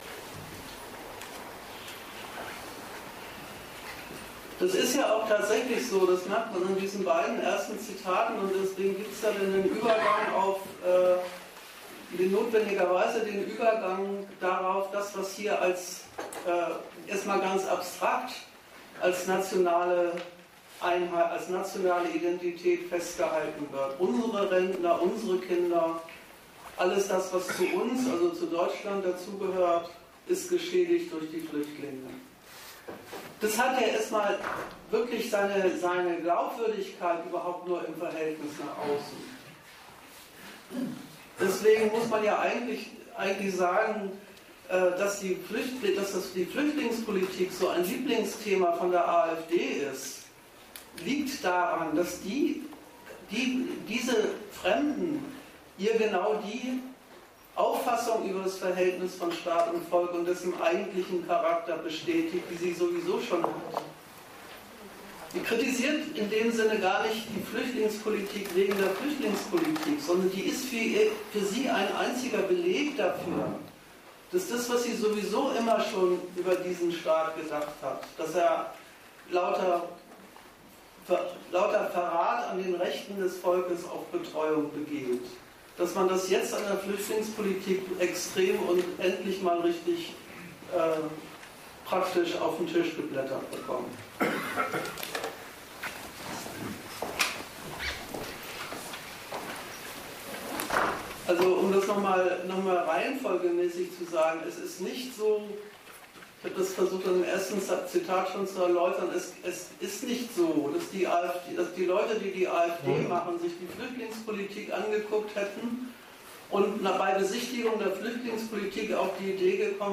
Ort Bremen
Dozent Gastreferenten der Zeitschrift GegenStandpunkt